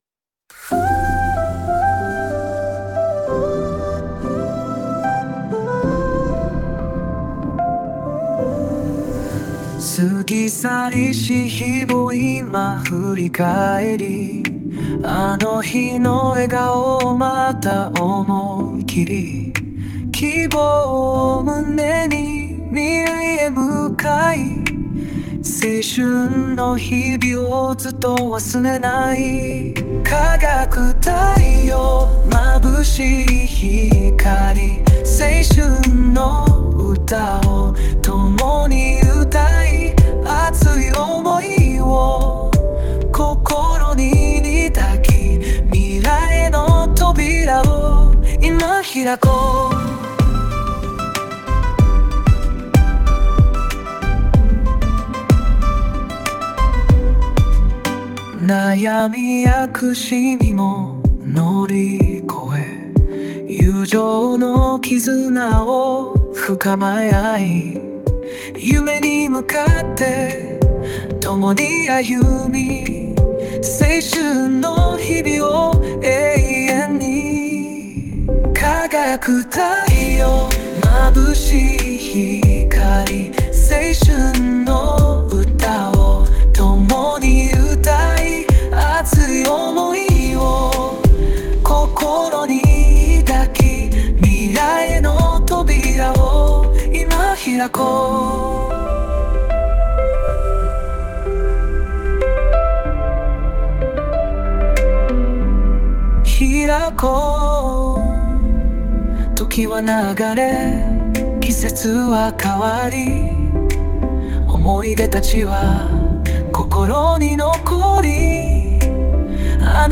生成した曲の例
[音楽のスタイル] JPOP, 男性, 陽気, 爽やか, 青春